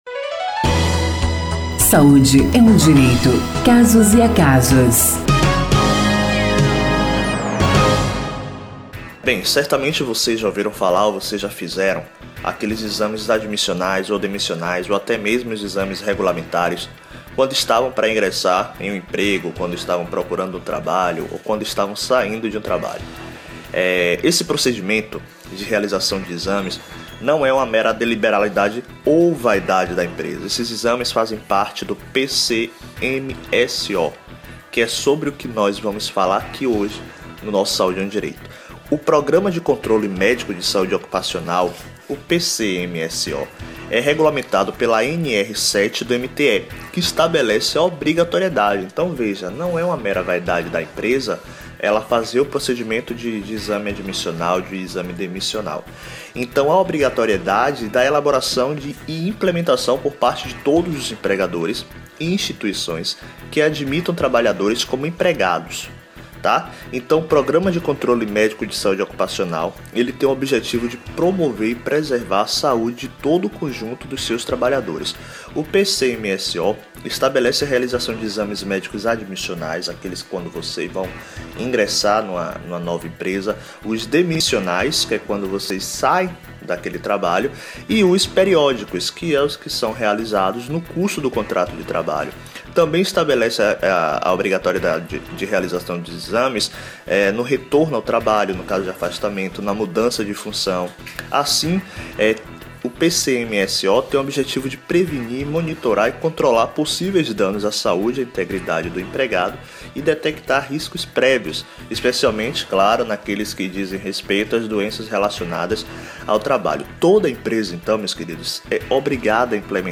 O tema foi abordado no quadro Saúde é um direito, que vai ao ar toda quarta-feira, no Programa Saúde no ar, veiculado pelas Rádios Excelsior AM840 e Rádio Saúde no ar.